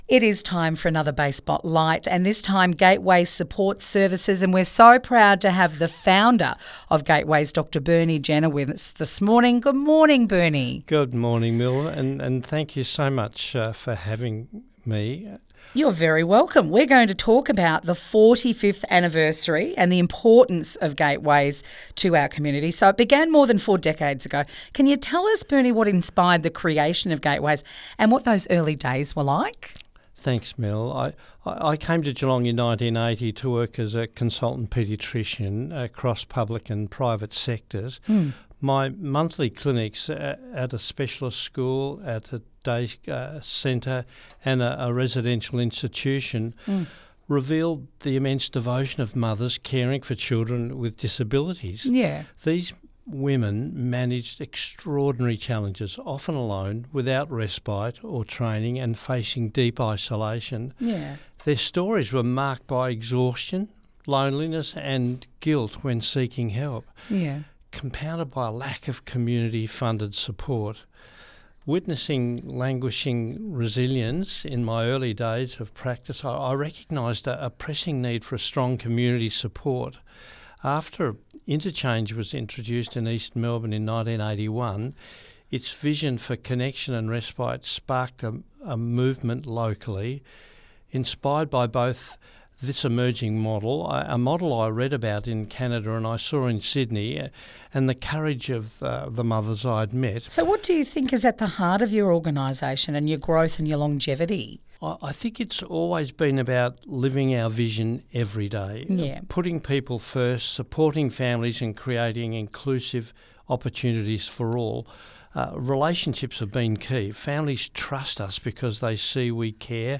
Bay Fm Interview (1)